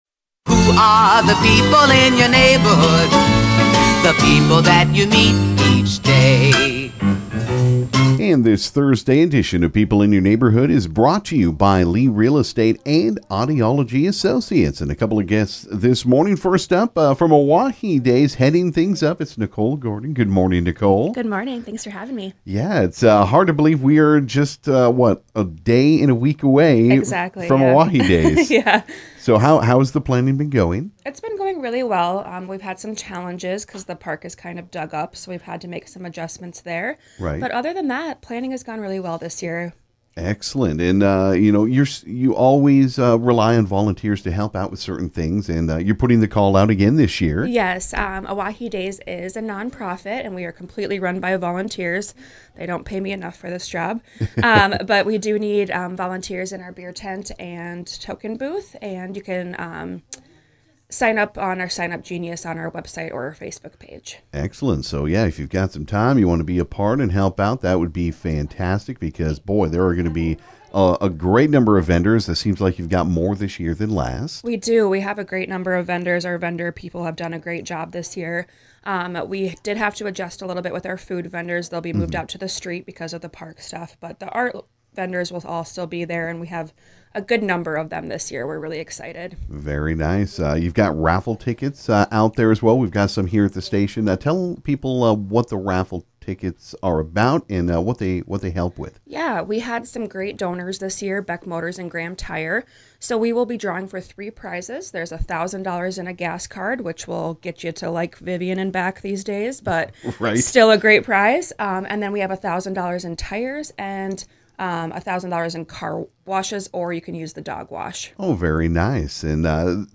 This morning there were a couple of guests on KGFX